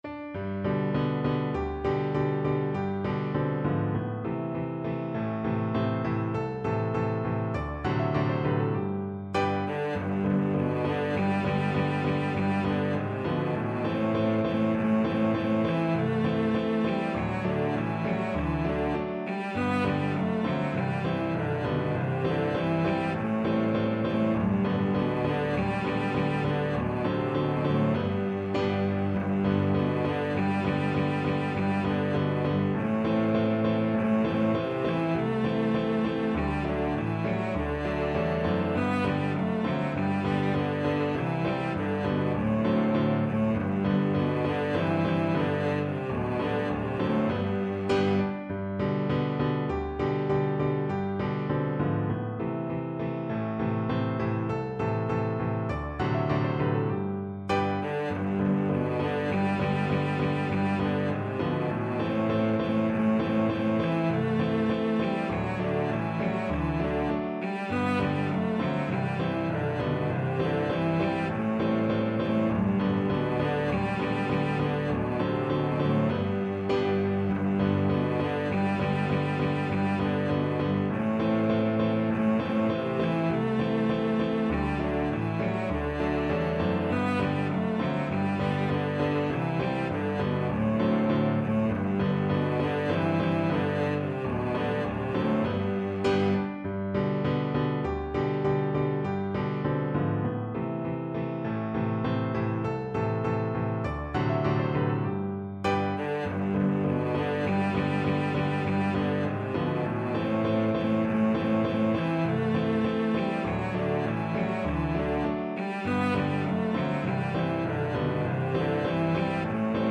2/2 (View more 2/2 Music)
Moderato
Cello  (View more Easy Cello Music)
Classical (View more Classical Cello Music)